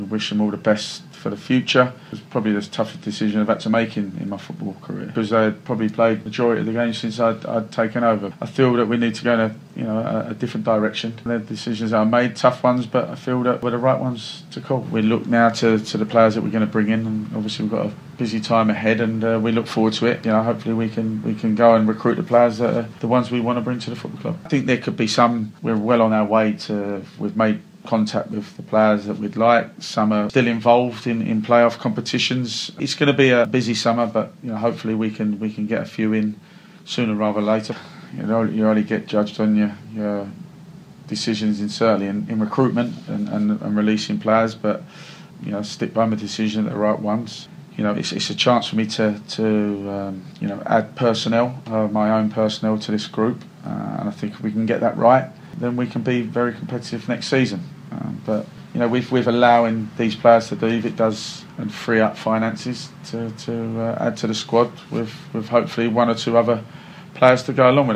Interview: Gillingham FC have released four players